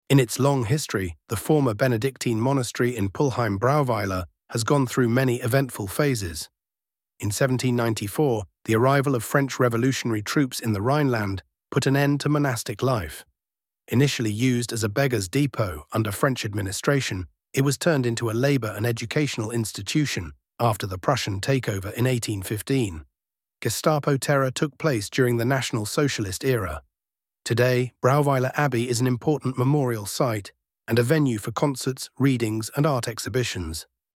audioguide-english-brauweiler-abbey.mp3